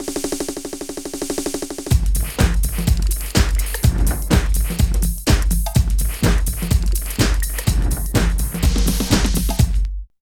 81 LOOP   -R.wav